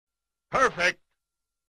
Perfect (Street Fighter) Sound Effect.mp3